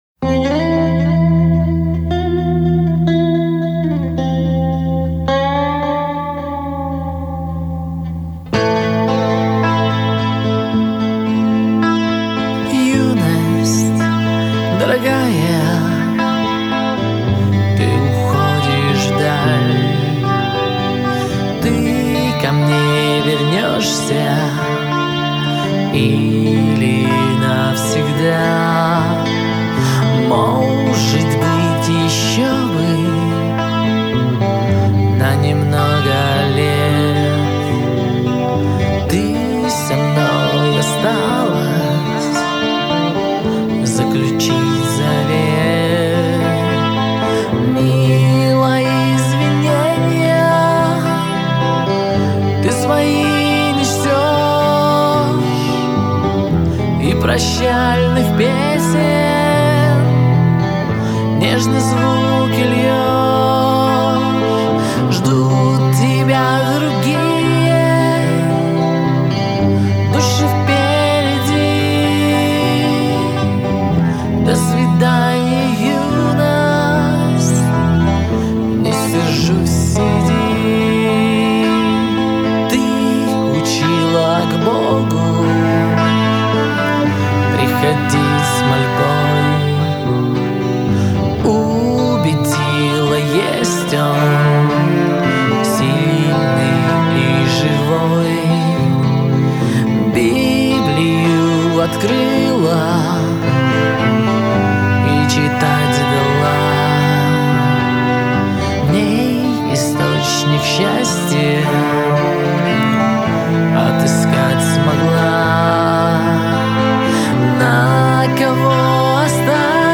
116 просмотров 238 прослушиваний 13 скачиваний BPM: 110